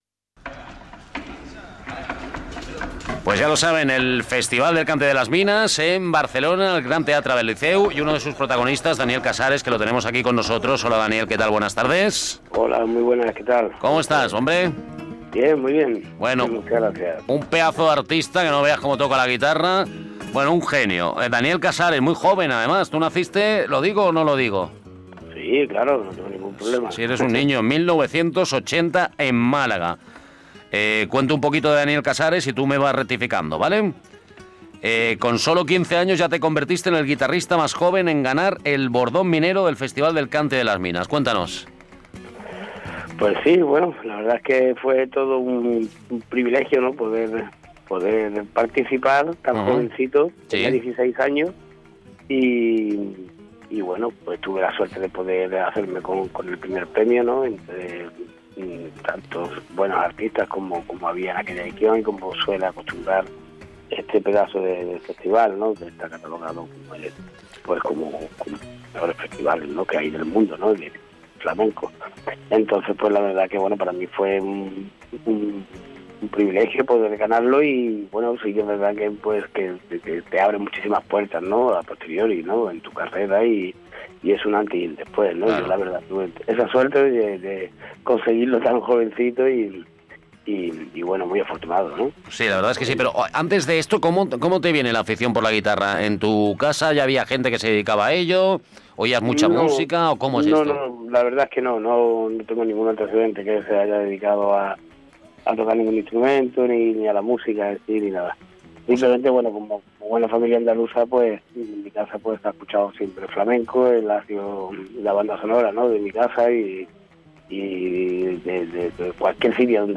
entrv-daniel-casares.mp3